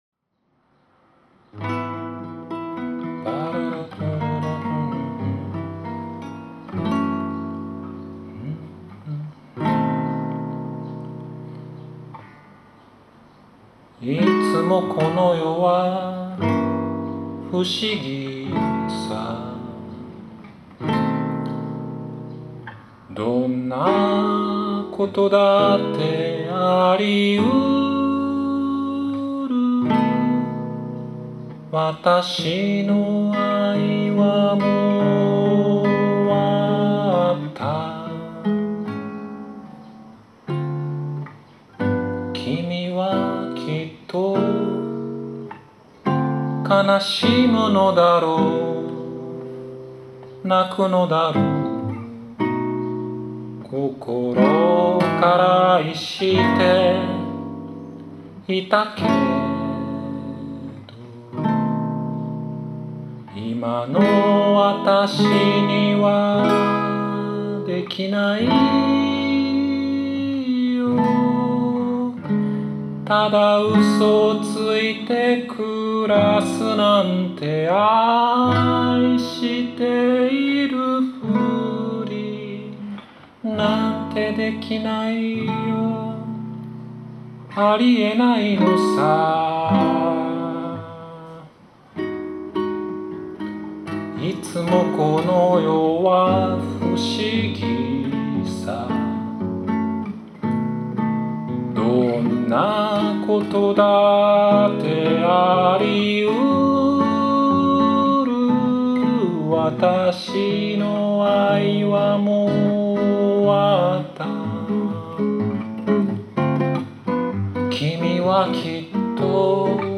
ボサノヴァというより、サンバです。
＊暑いので演奏や録音がいい加減なのはどうかお許しを。